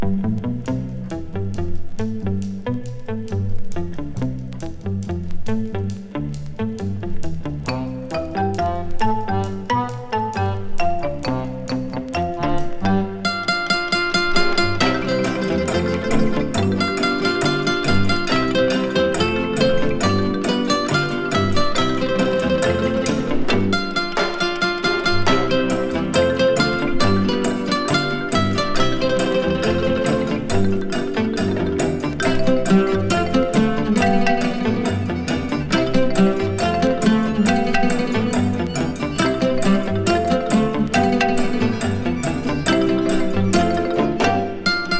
Jazz, Pop, World, Easy Listening　USA　12inchレコード　33rpm　Stereo